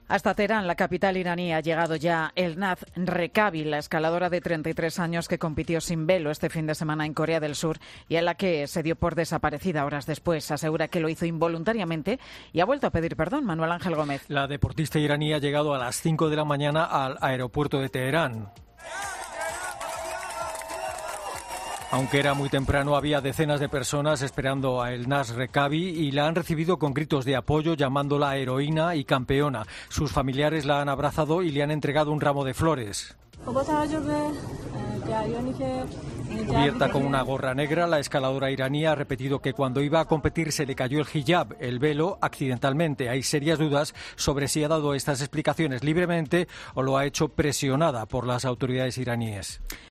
Decenas de personas reciben a la escaladora Elnaz Rekabi en el aeropuerto de Teherán.